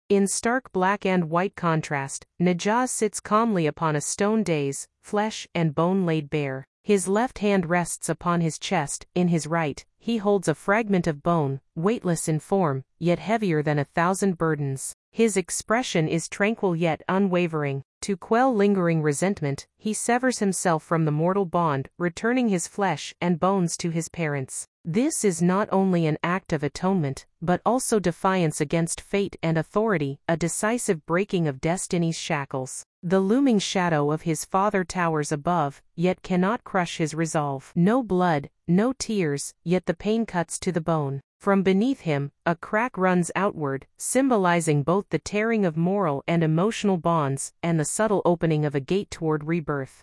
English audio guide